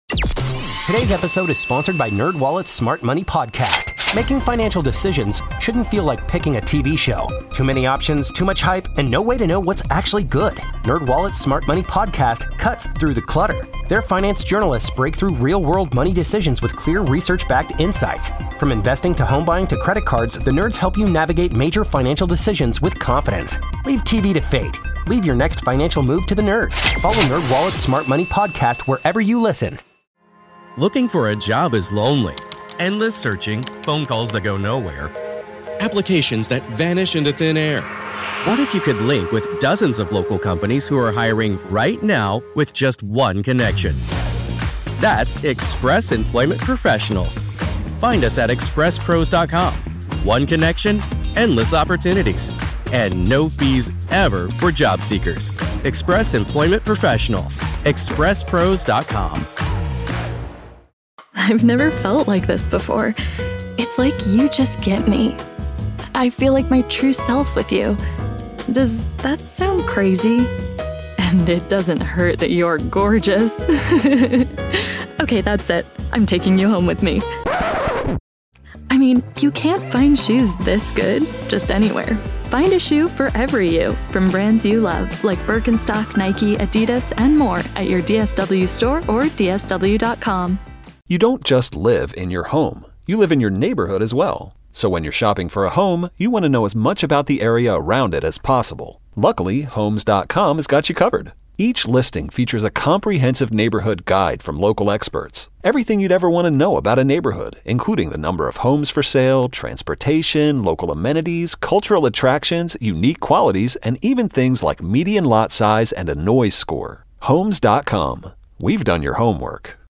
Live from Mulligan's in Wildwood!